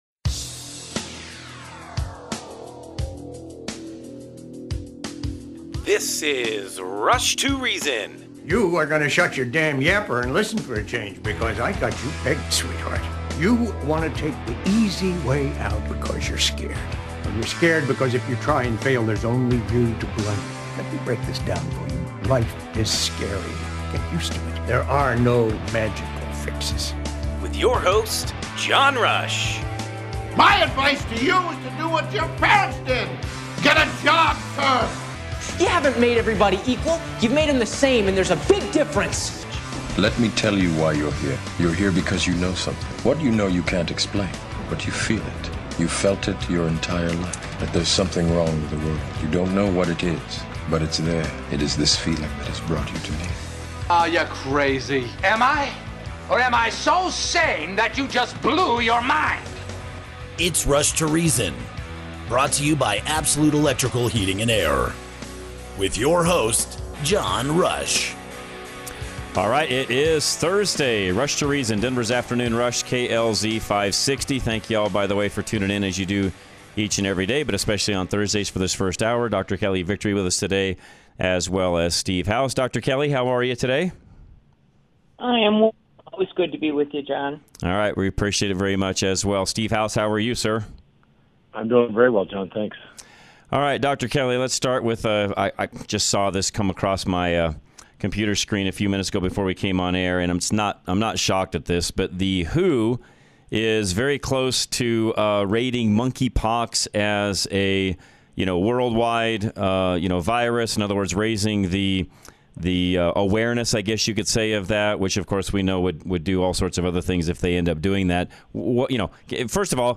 Rush To Reason - Interviews COVID: Silencing the Doctors.